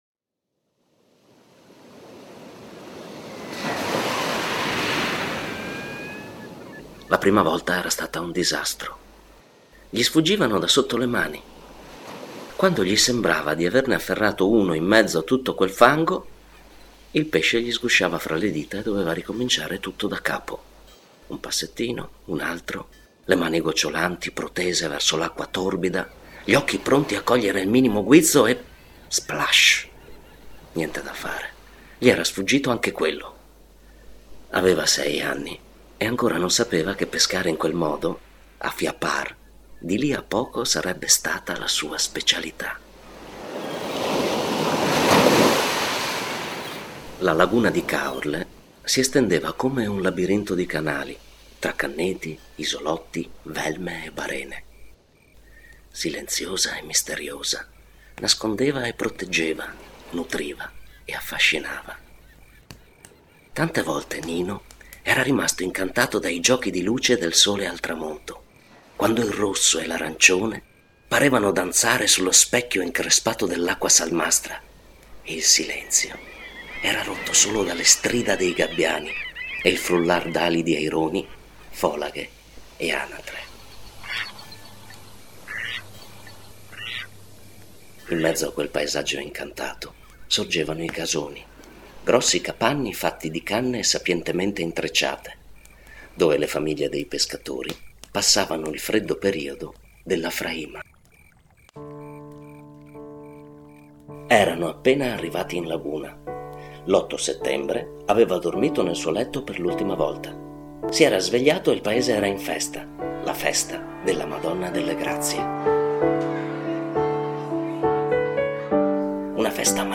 Rielaborando alcuni racconti dei testimoni è stato realizzato un fumetto che permette ai bambini di ripercorrere la vita di un loro coetaneo vissuto nella prima metà del Novecento. Il racconto è stato anche interpretato da un attore e musicato per realizzare una comoda audiostoria.